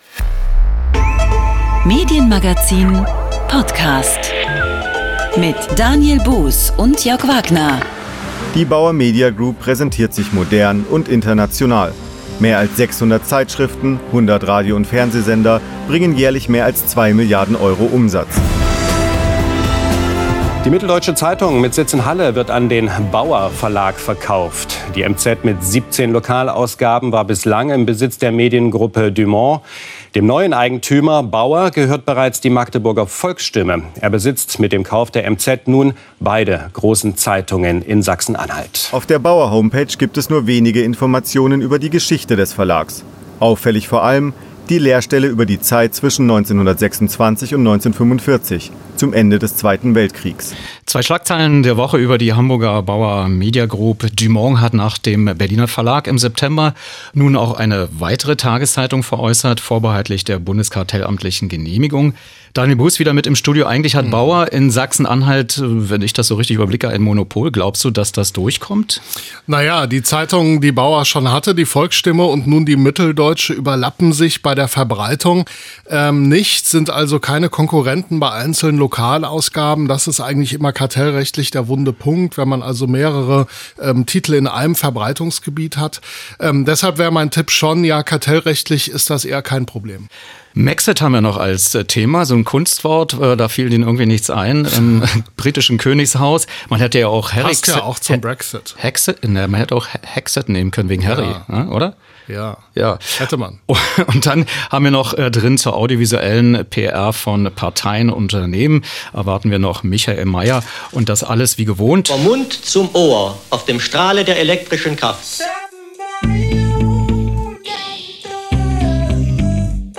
O-Ton-Collage: Schlagzeilen der Woche
Telefoninterview